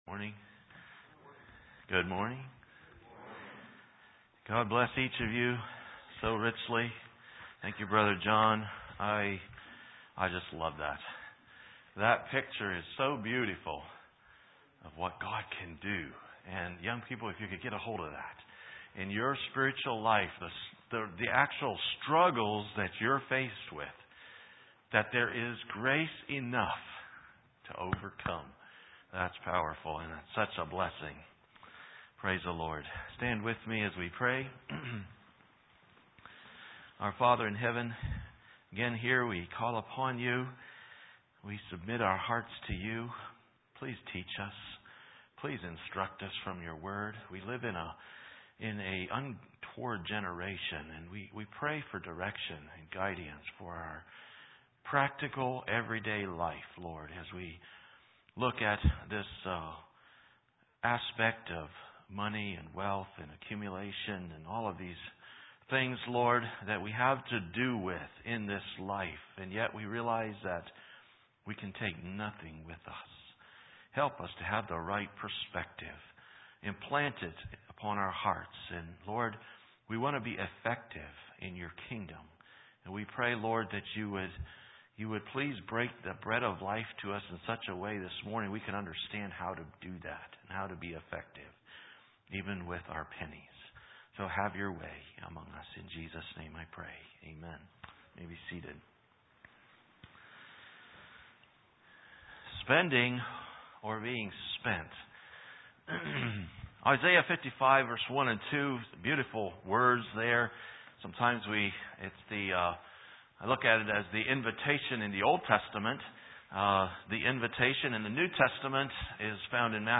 Sermon set